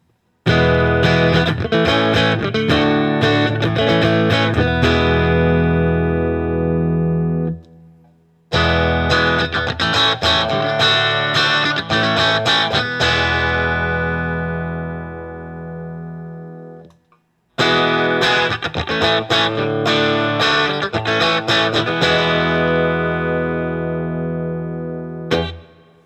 Open Chords #2
As usual, for these recordings I used my normal Axe-FX Ultra setup through the QSC K12 speaker recorded into my trusty Olympus LS-10.
For each recording I cycled through the neck pickup, both pickups, and finally the bridge pickup.